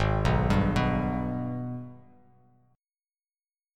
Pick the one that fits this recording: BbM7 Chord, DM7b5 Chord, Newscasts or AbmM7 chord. AbmM7 chord